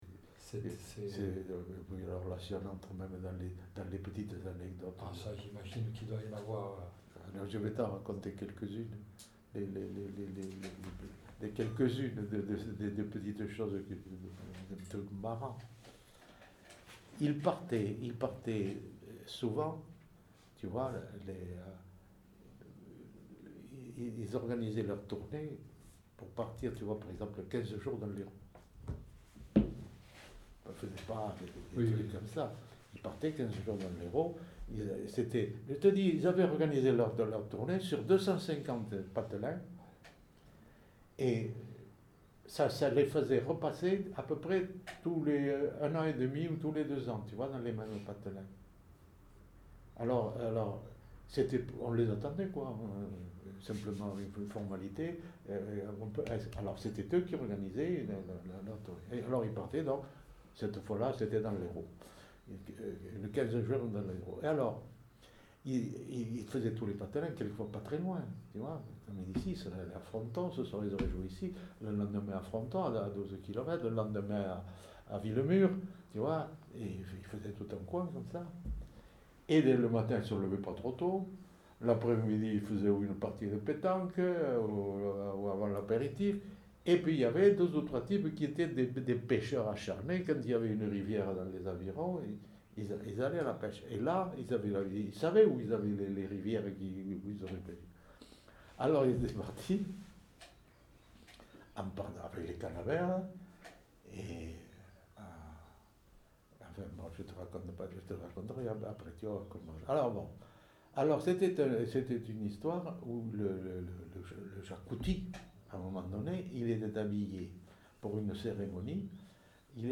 Lieu : Saint-Sauveur
Genre : conte-légende-récit
Type de voix : voix d'homme